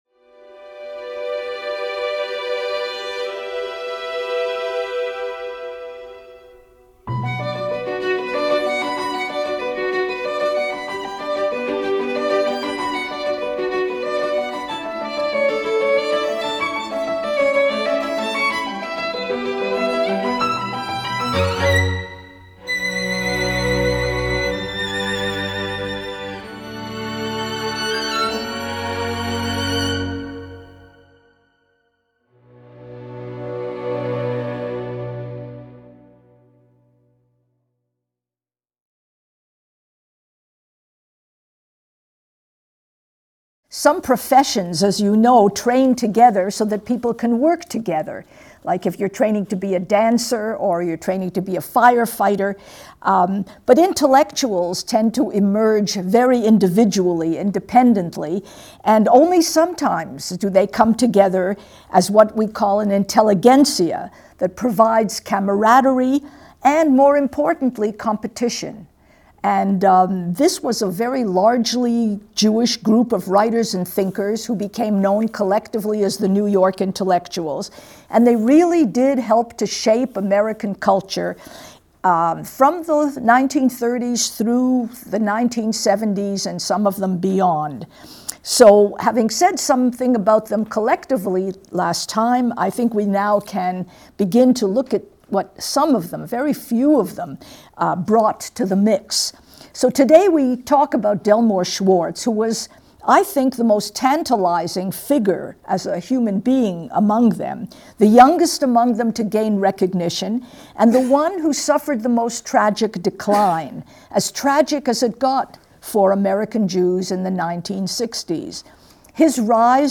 In our second lecture, Professor Wisse discusses the poet and critic Delmore Schwartz.